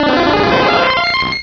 Cri de Parasect dans Pokémon Rubis et Saphir.